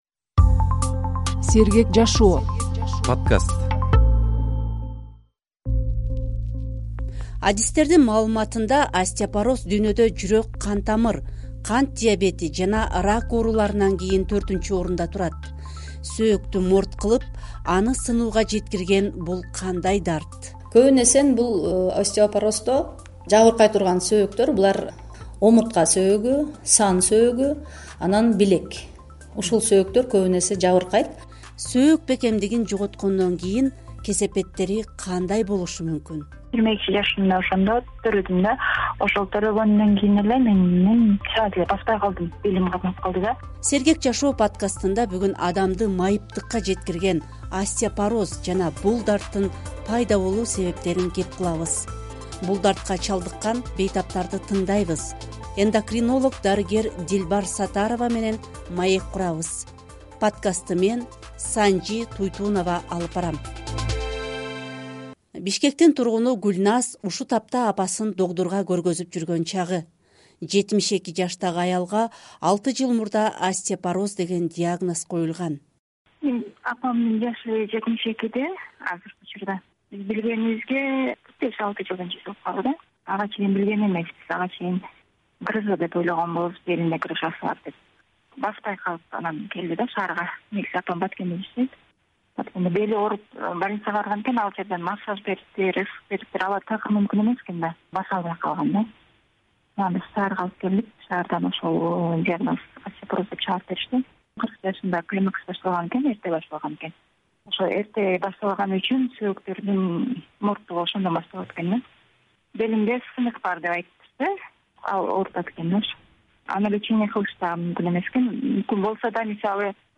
“Сергек жашоо” подкастында бүгүн адамды майыптыкка жеткирген остеопороз жана бул дарттын пайда болуу себептерин кеп кылабыз. Бул дартка чалдыккан бейтаптарды тыңдайбыз.